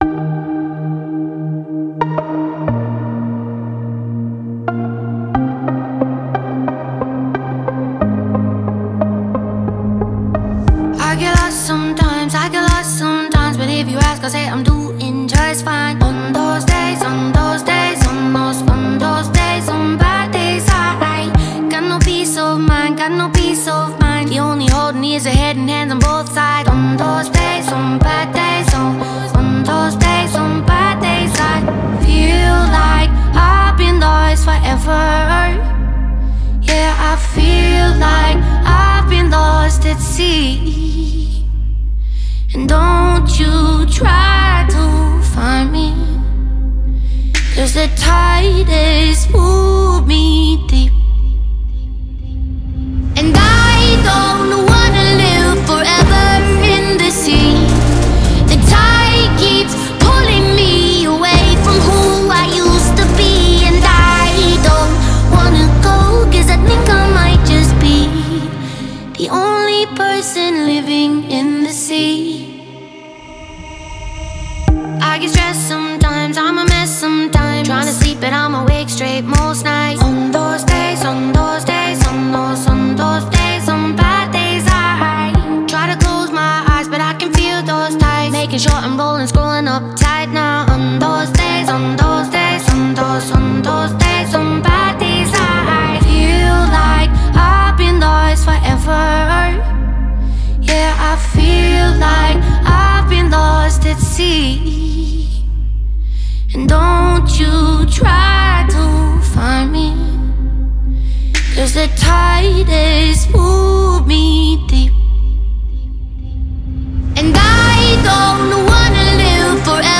который сочетает в себе элементы поп и инди.